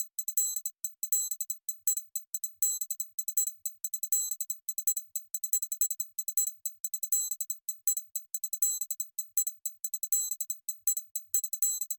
描述：潍坊三角环
Tag: 160 bpm Trap Loops Percussion Loops 2.02 MB wav Key : Unknown